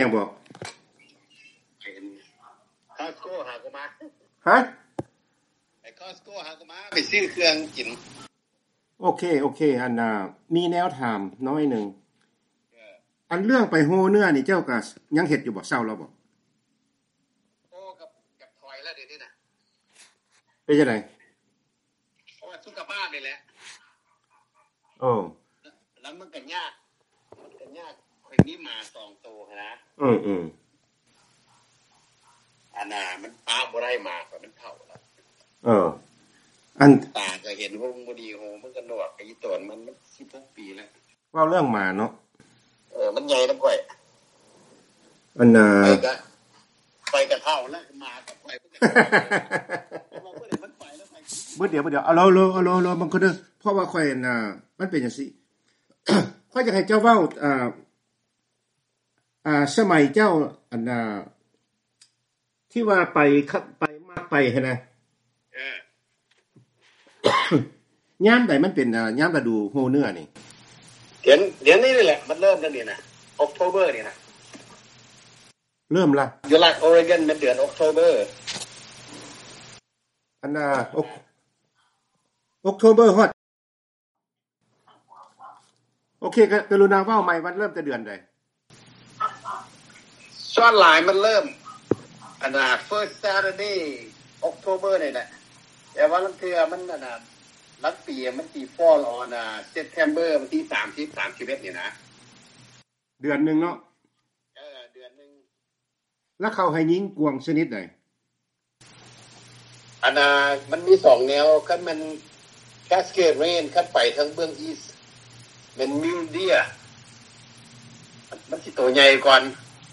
ວີໂອເອລາວ ໂອ້ລົມກັບບັນດານັກລ່າເນື້ອ ລາວອາເມຣິກັນ ກ່ຽວກັບປະສົບການ ຂອງພວກເຂົາເຈົ້າ